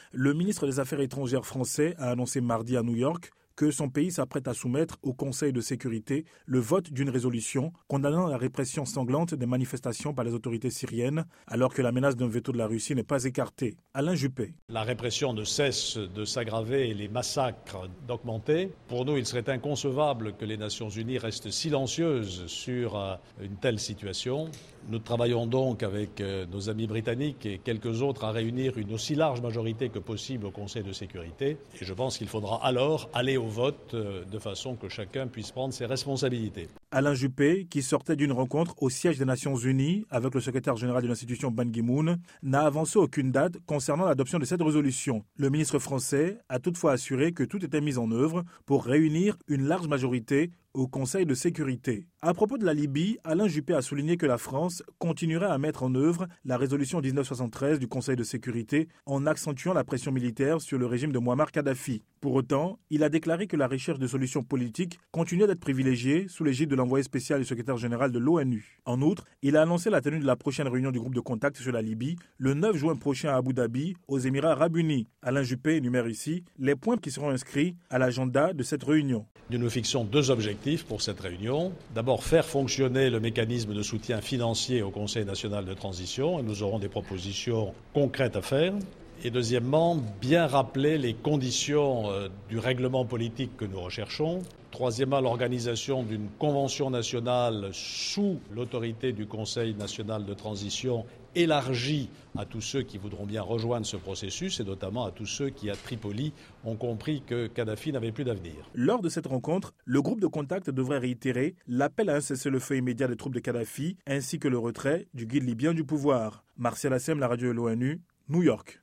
En extrait audio, Alain Juppé, ministre des affaires étrangères français qui s'est prononcé à New York en faveur du vote d’une résolution condamnant la répression sanglante des manifestations par les autorités syriennes.